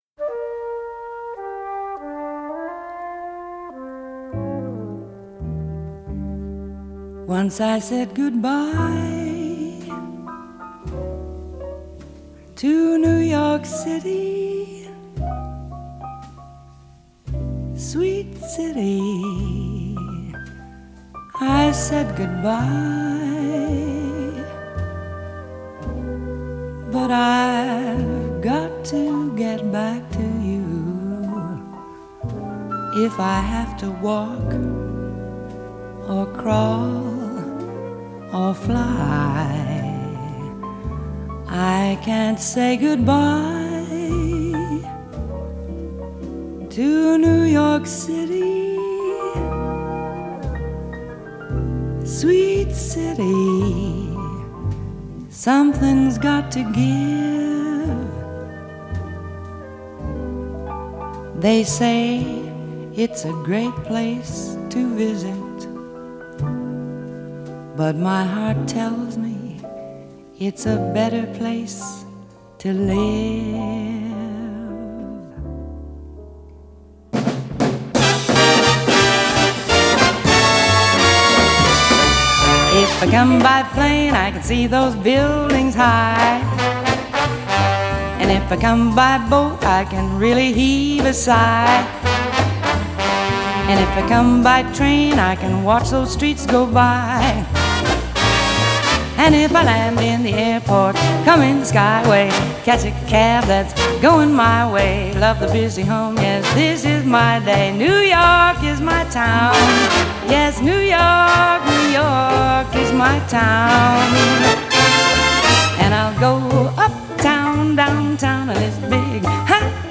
1962   Genre: Pop   Artist